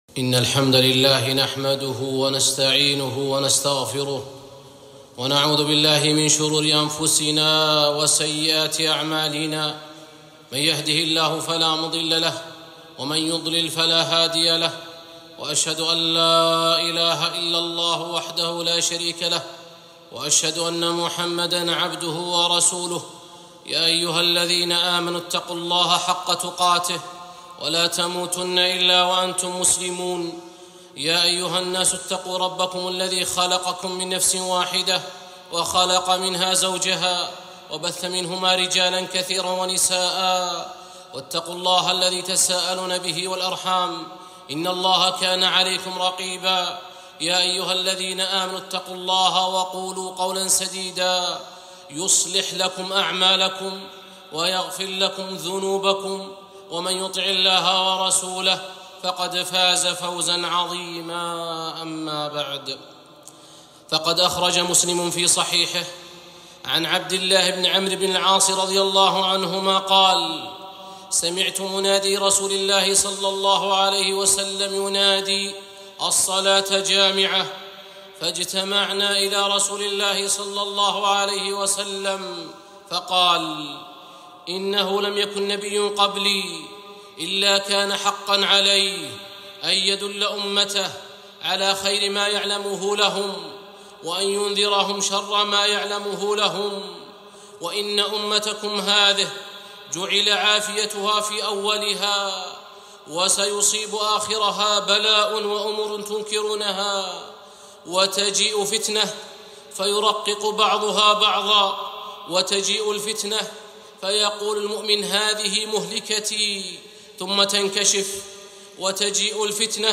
خطبة - من أسباب الفتن